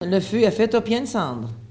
Sallertaine
Locutions vernaculaires